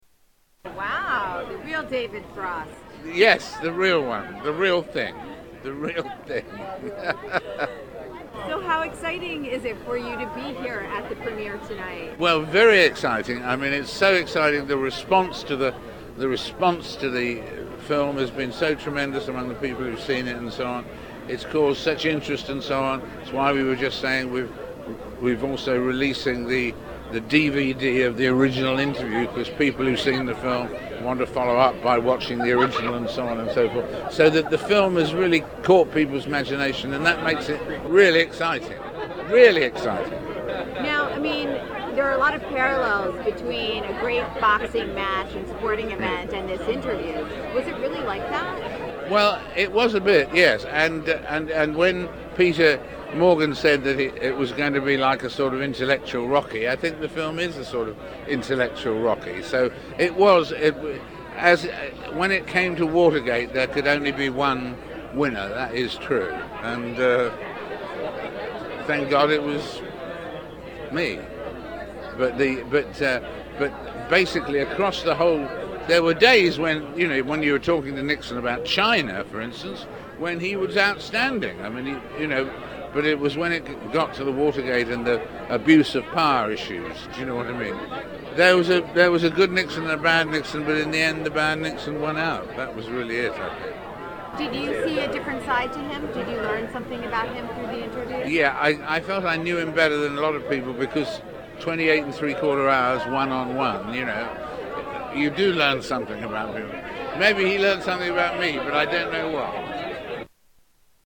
David Frost talks about the movie
Tags: Historical Frost Nixon Interview Audio David Frost Interviews Richard Nixon Political